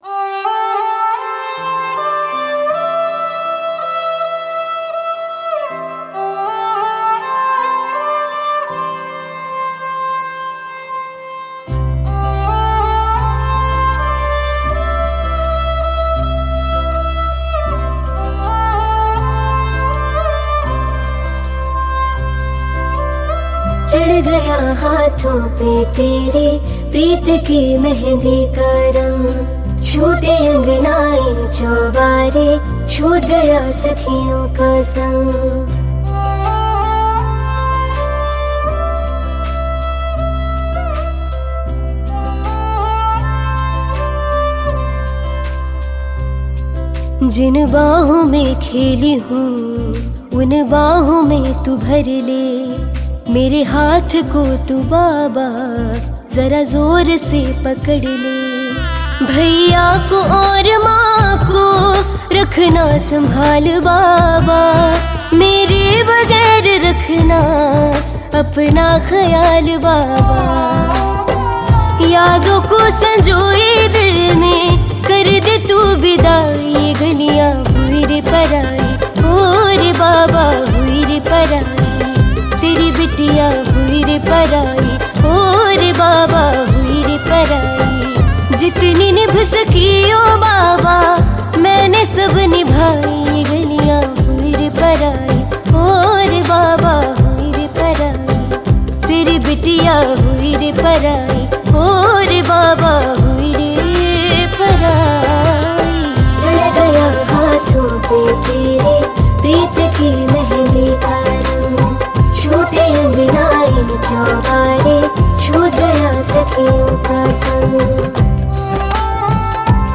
Hindi Pop Album Songs 2023